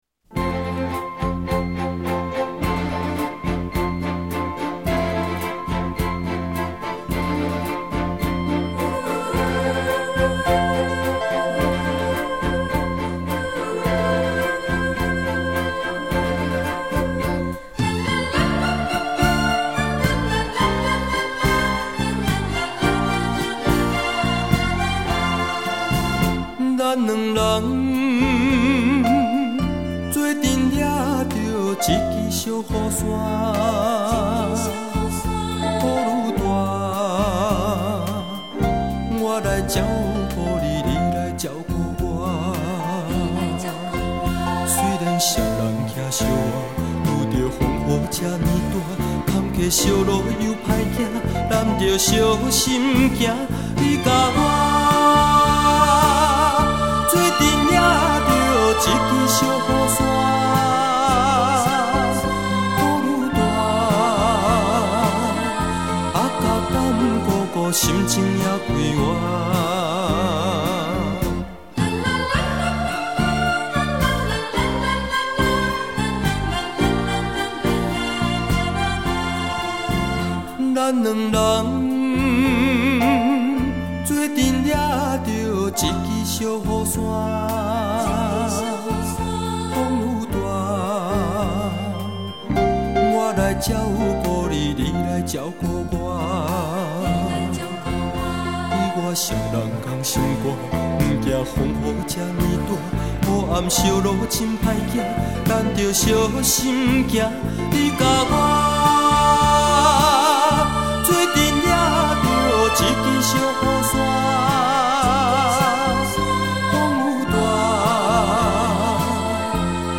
闽南语歌曲专辑